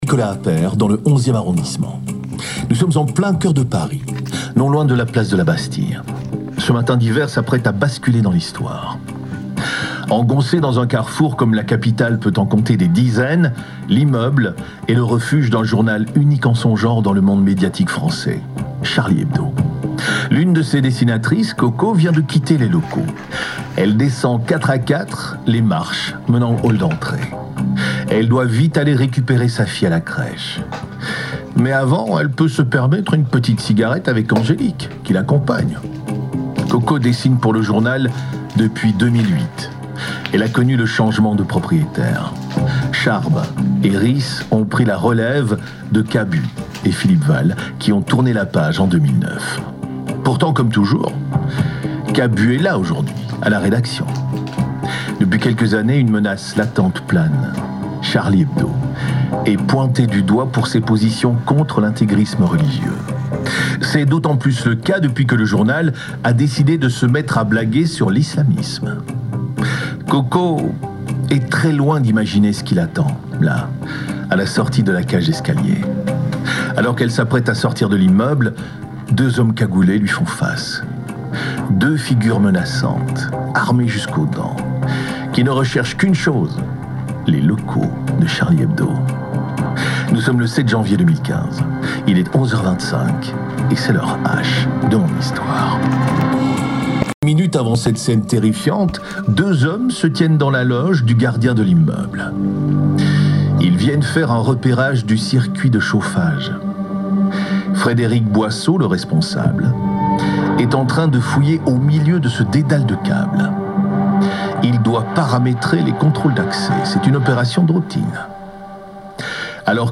Gilles Kepel était l'invité ce matin à la Première pour parler de l'islam, de ce qui a été à la base de ce qui s'est passé en 2015 à Paris et ailleurs dans une recrudescence du terrorisme.